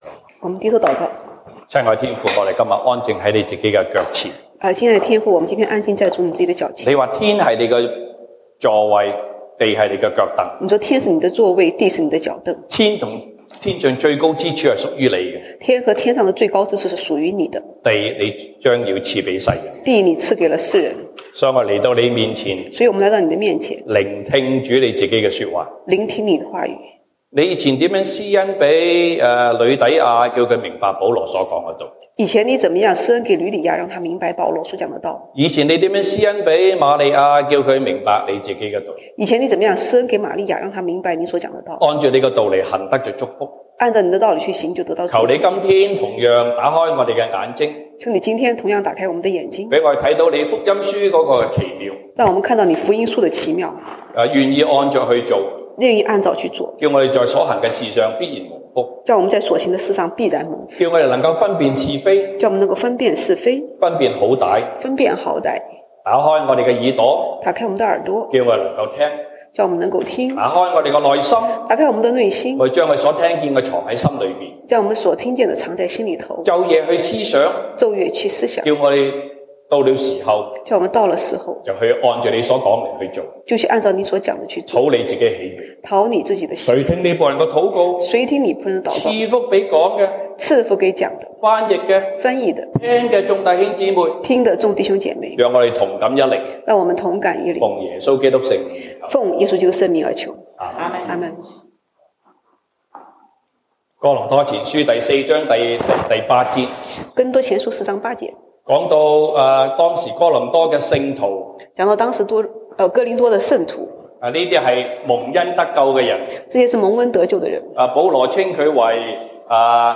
西堂證道(粵語/國語) Sunday Service Chinese: 基督的僕人像甚麼?
1 Corinthians Passage: 歌林多前書 1 Corinthians 4:8-21 Service Type: 西堂證道(粵語/國語) Sunday Service Chinese Topics